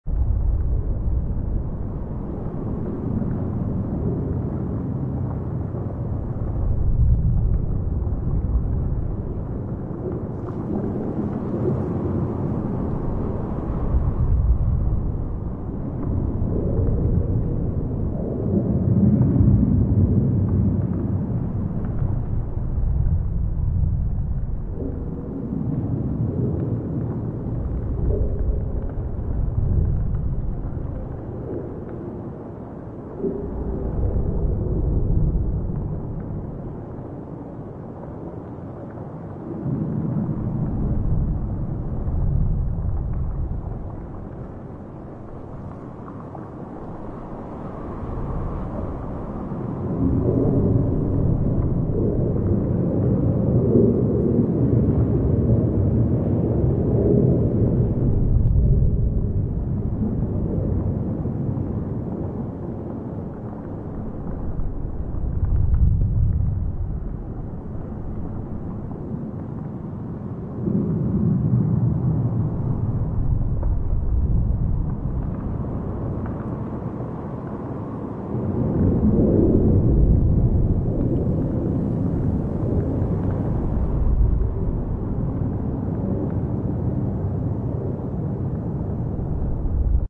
zone_field_asteroid_nomad.wav